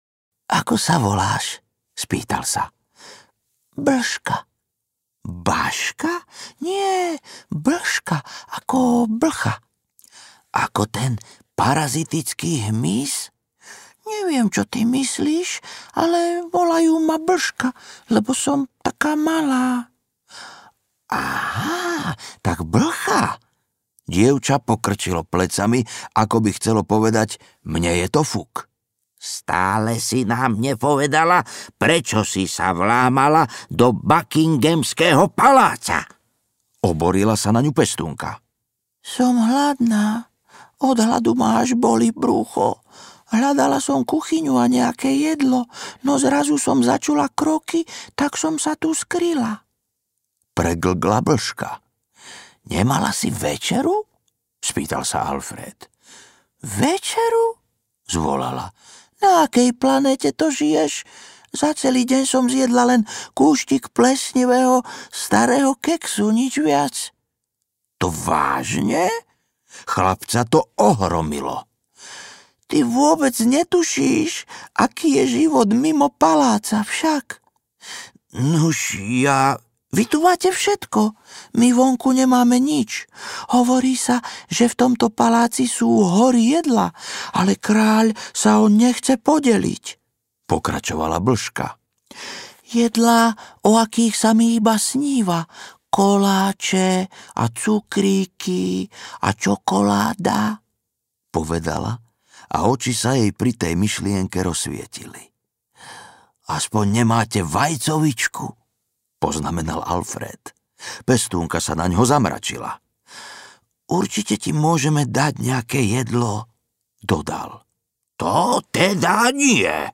Beštia z Buckinghamu audiokniha
Ukázka z knihy